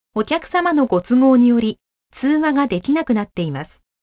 ■着信拒否アナウンス３